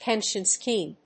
音節pénsion schème発音記号・読み方pénʃ(ə)n-